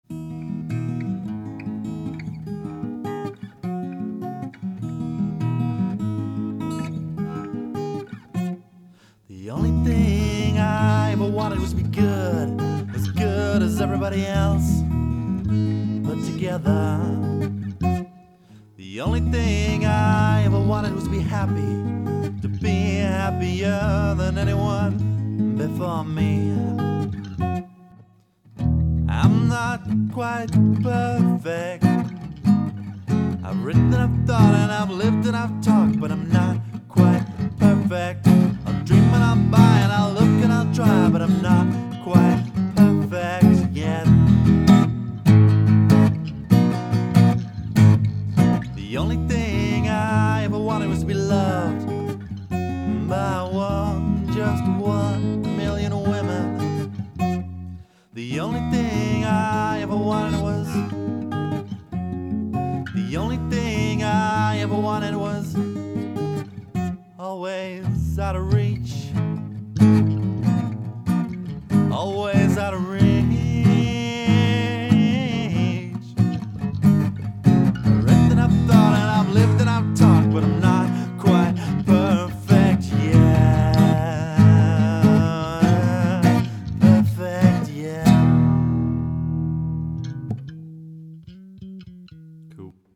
The first day I got to mess around in a proper studio
bass